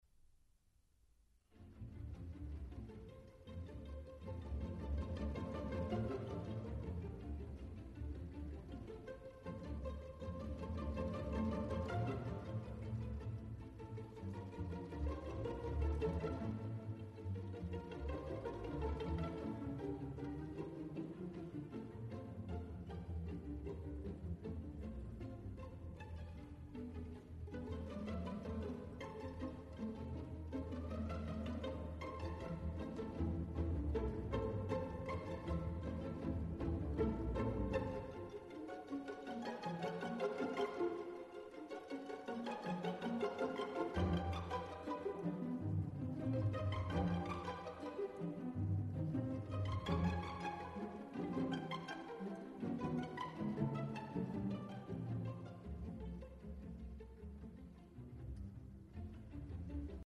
Great Classical Music